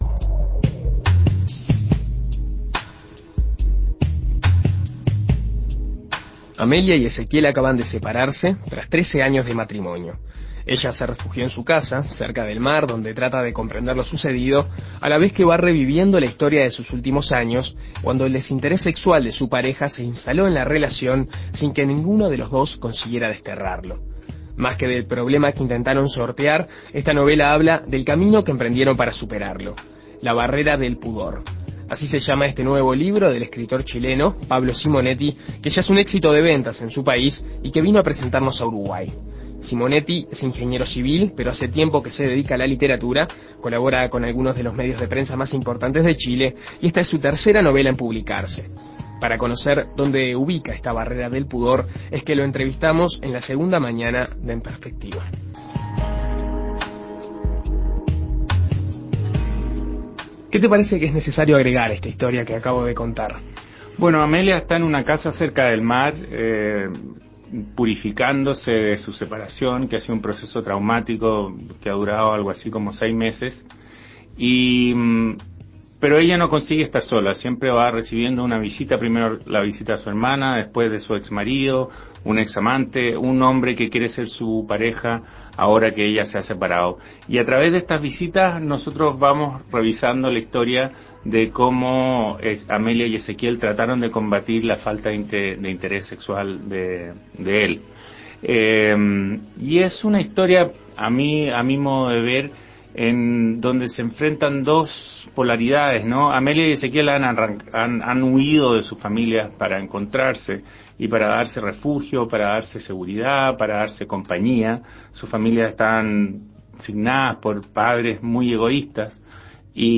En Perspectiva Segunda Mañana dialogó con el autor para conocer los detalles de la obra.